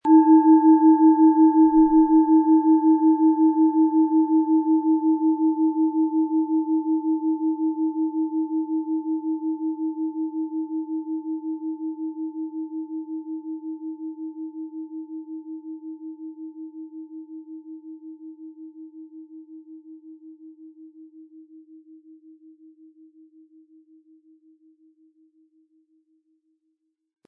Es ist eine Planetenklangschale Wasserstoffgamma aus einem kleinen Meisterbetrieb in Asien.
• Mittlerer Ton: Mond
Im Sound-Player - Jetzt reinhören können Sie den Original-Ton genau dieser Schale anhören.
Der gratis Klöppel lässt die Schale wohltuend erklingen.
PlanetentöneWasserstoffgamma & Mond
MaterialBronze